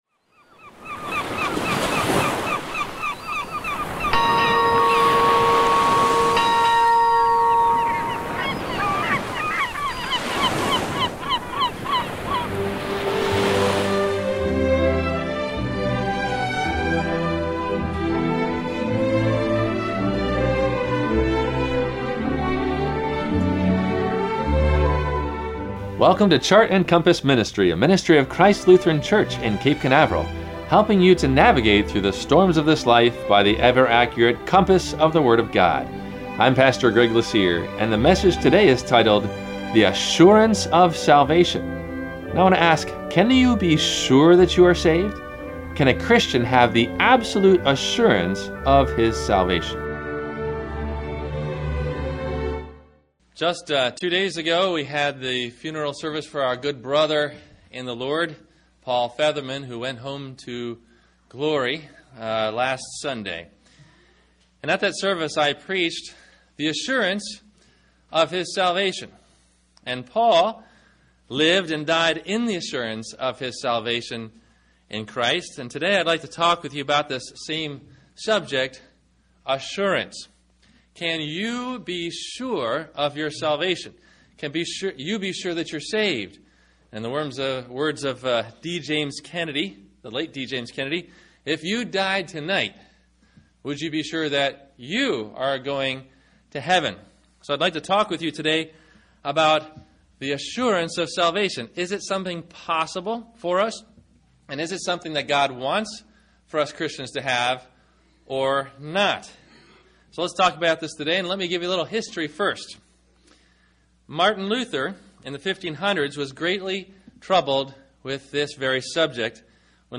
The Assurance of Salvation – WMIE Radio Sermon – September 29 2014